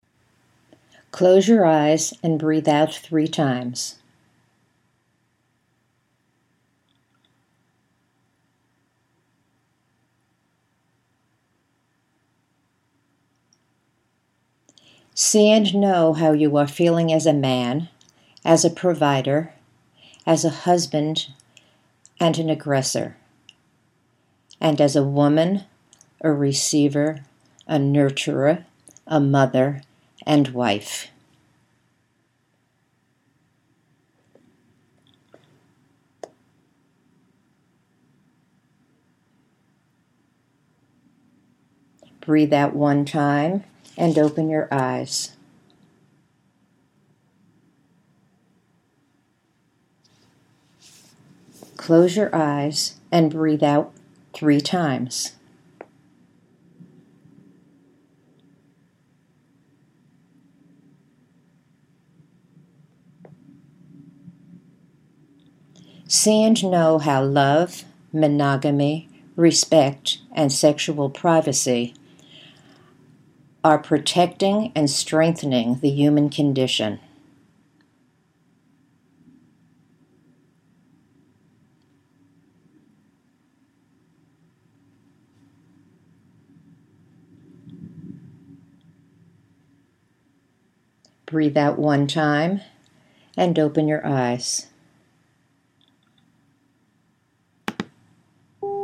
At the end of each exercise you’ll find a quiet space of 8 seconds to focus on your images.
The simple version: until you hear the beep, there may be another instruction.